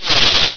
Static3
STATIC3.WAV